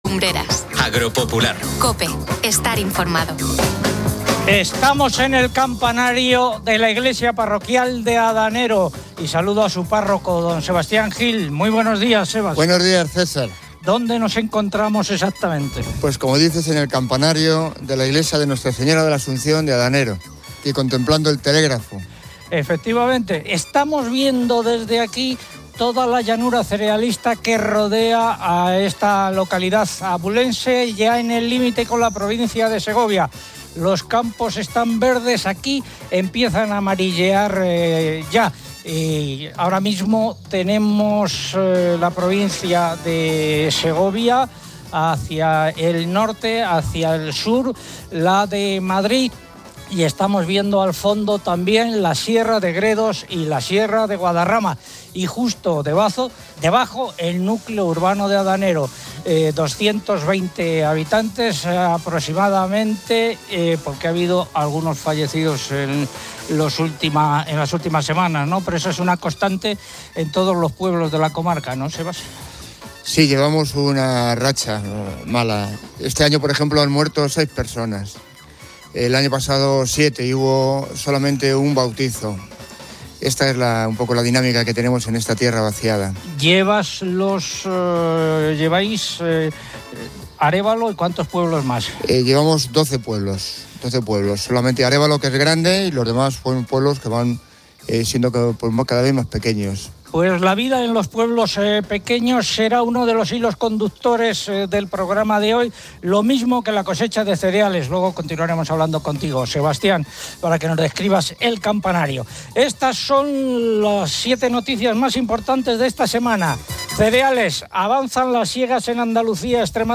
Un programa de radio que da voz al campo, escucha las principales reivindicaciones y lucha por dotar a este sector de las fortalezas que necesita. Información, entrevistas y reportajes sobre el sector terciario.